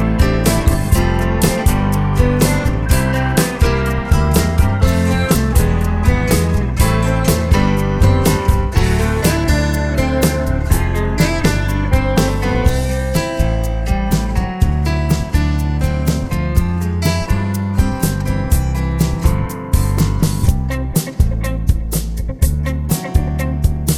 One Semitone Down Jazz / Swing 3:31 Buy £1.50